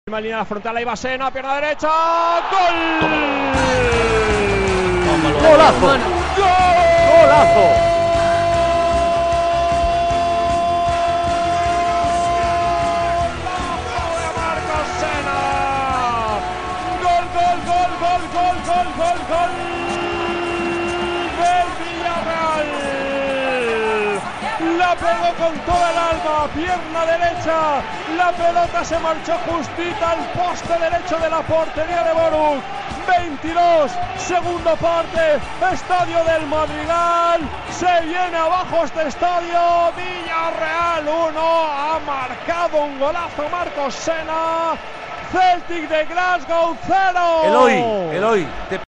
Narració del gol de Marcos Senna en el partit Villareal Celtic de Gasgow de la segona jornada de la fase de grups de la Lliga de Campions masculina (El partit va acabar 1 a 0)
Esportiu